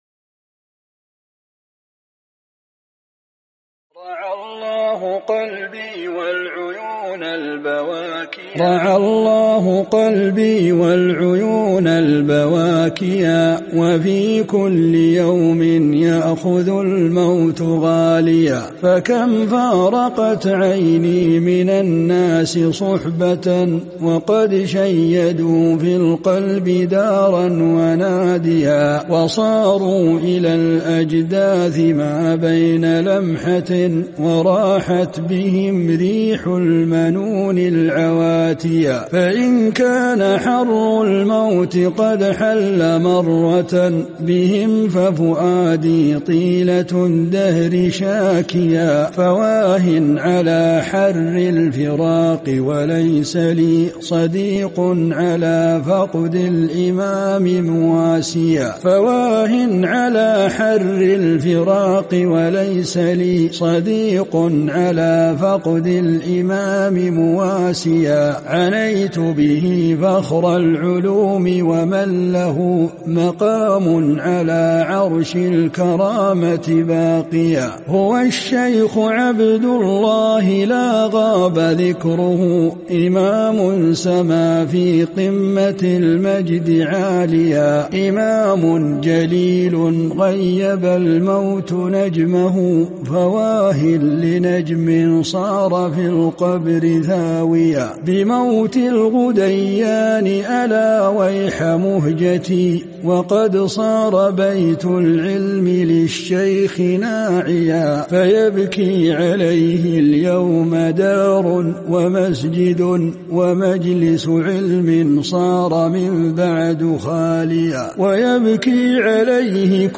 قصيدة: مرثية في الشيخ العلامة ابن غديان شعر